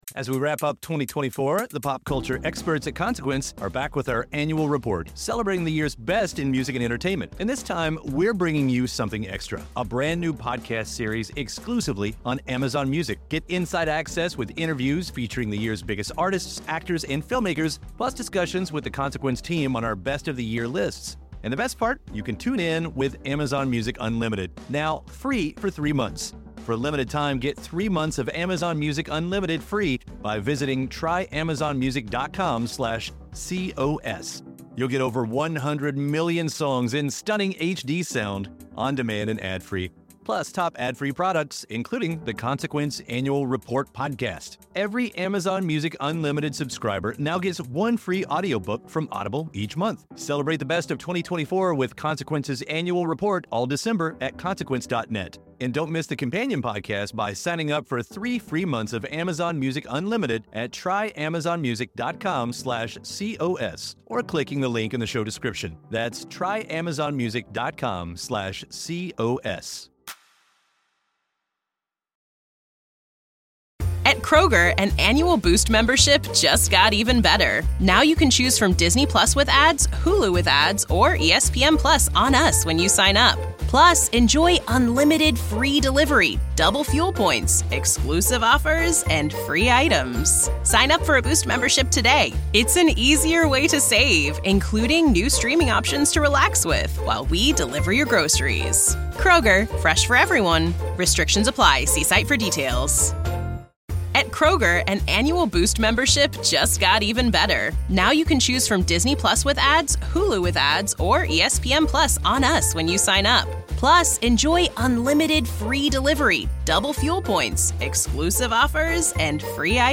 an interview series